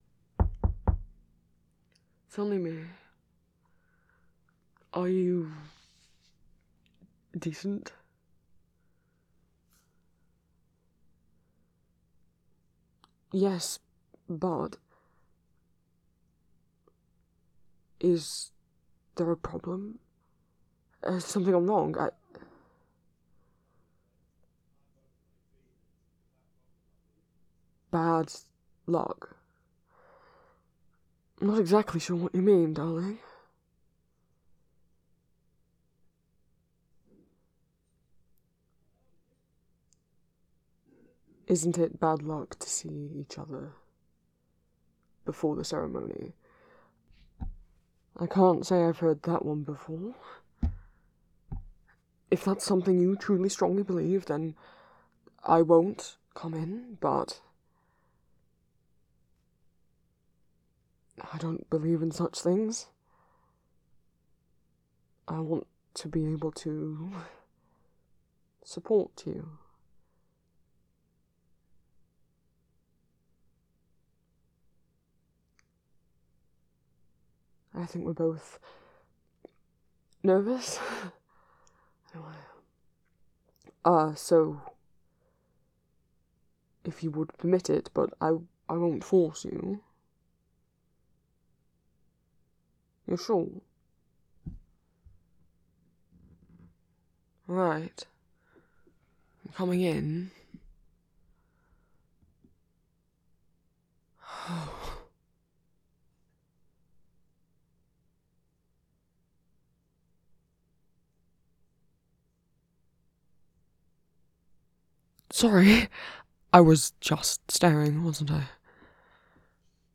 [M4M] [Part 6] [Established relationship] [Romantic] [Kisses]